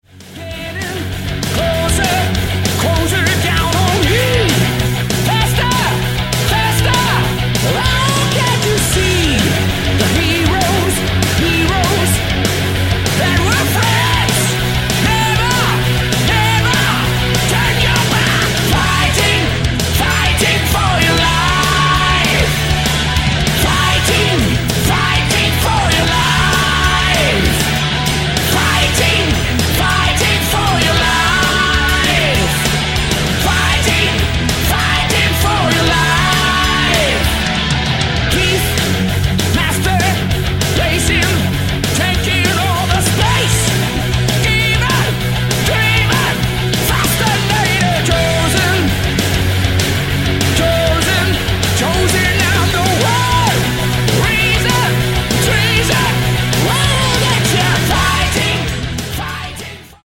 Lead Vocals
Bass
Guitars, and Bass
Drums and Percussion
Keyboards and Hammond